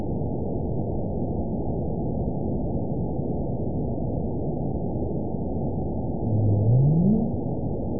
event 917805 date 04/17/23 time 01:02:16 GMT (2 years ago) score 9.37 location TSS-AB05 detected by nrw target species NRW annotations +NRW Spectrogram: Frequency (kHz) vs. Time (s) audio not available .wav